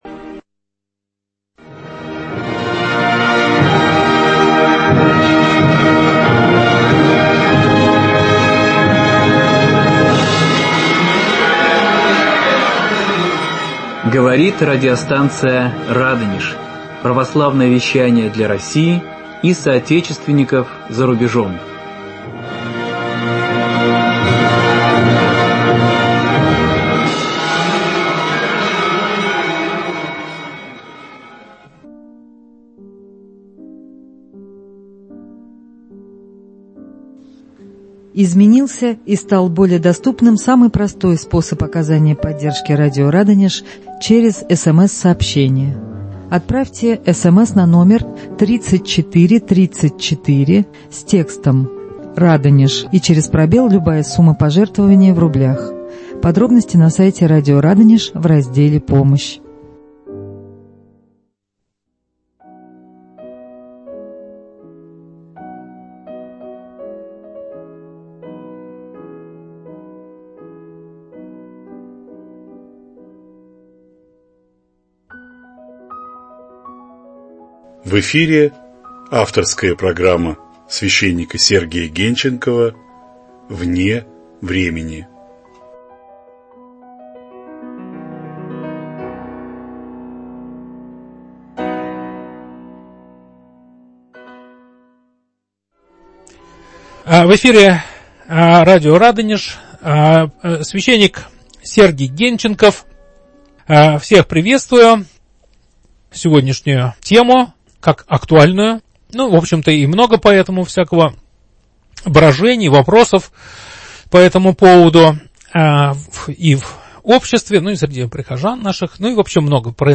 Что нужно сделать для того, чтобы современное образование служило не только достижению профессиональных успехов, но также способствовало спасению души человека?.. В прямом эфире радиостанции "Радонеж"